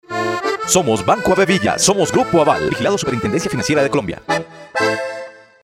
Voz en Off, Voice Over Latino, Juvenil, enérgico y creible
Sprechprobe: Industrie (Muttersprache):